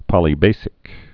(pŏlē-bāsĭk)